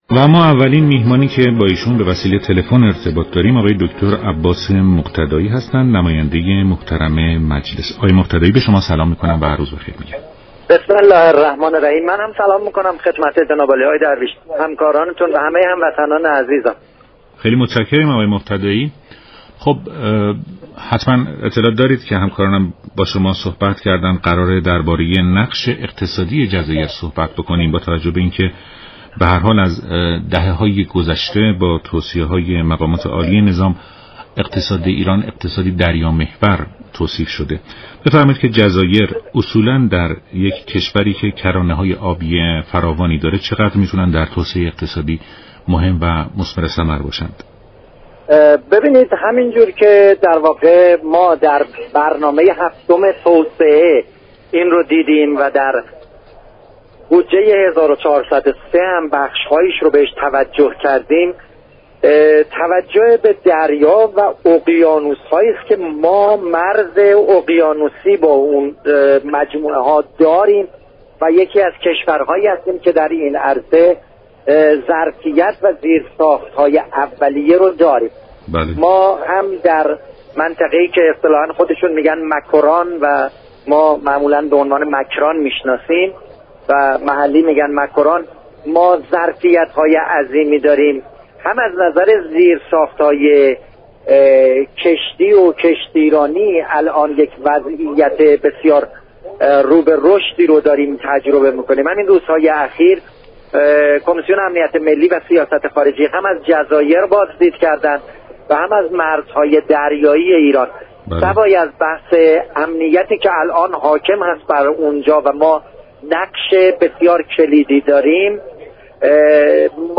نایب رئیس كمسیون امنیت ملی و سیاست خارجی مجلس در برنامه ایران امروز گفت: كمیسیون امنیت ملی و سیاست خارجی مجلس در هفته‌های آینده گزارشی را در خصوص جزایر ایرانی ارائه خواهد داد.